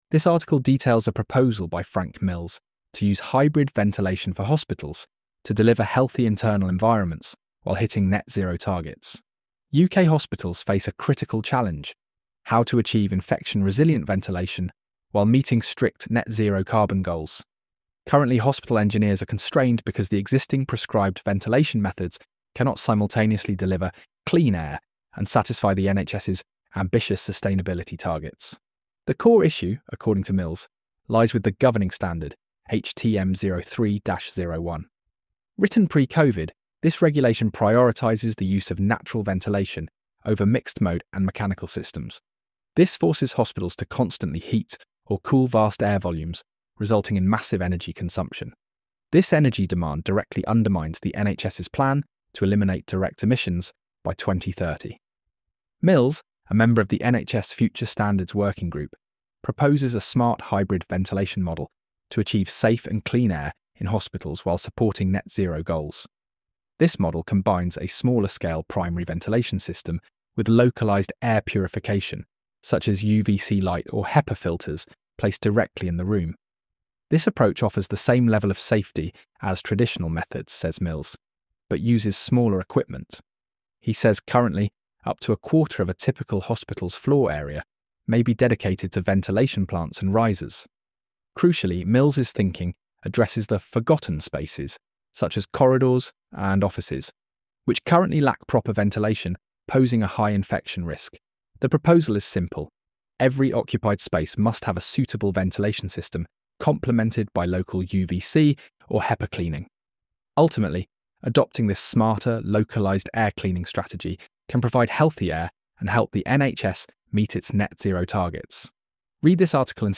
AI overview of this article